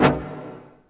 DreChron TubaHit.wav